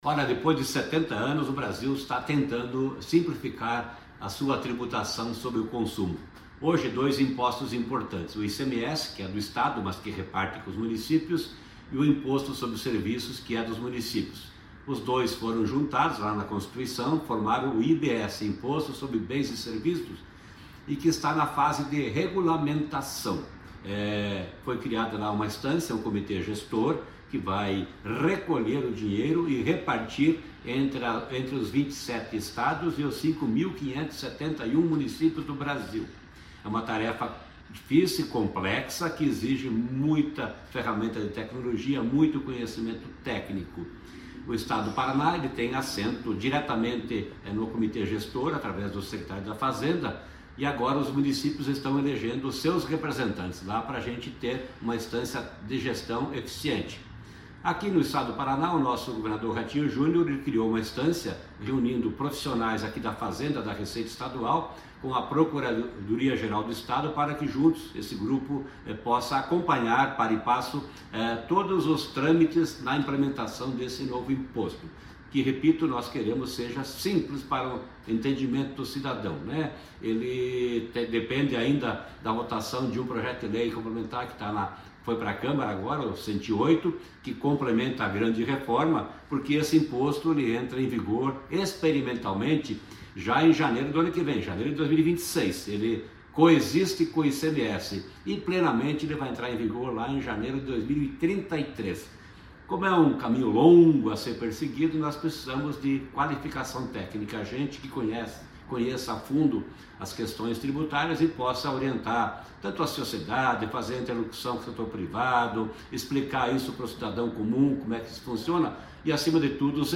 Sonora do secretário da Fazenda, Norberto Ortigara, sobre o Conselho Interinstitucional de Implantação da Reforma Tributária